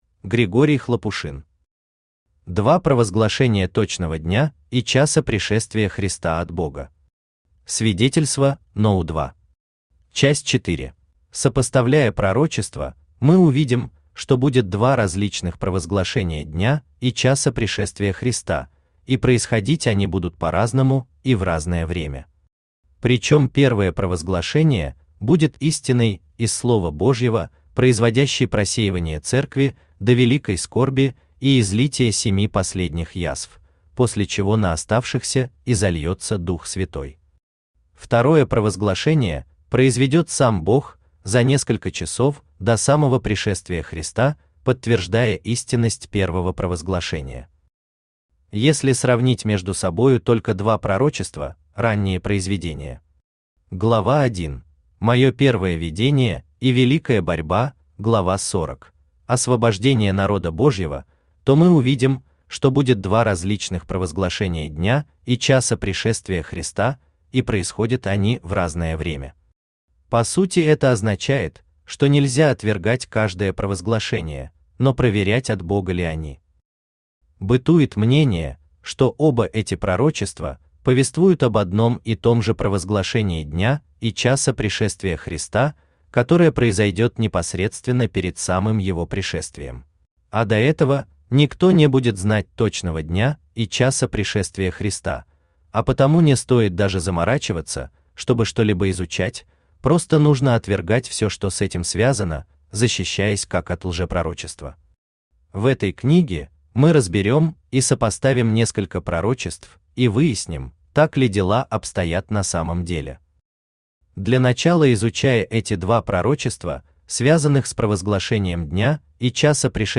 Аудиокнига Два провозглашения точного дня и часа пришествия Христа от Бога.
Читает аудиокнигу Авточтец ЛитРес.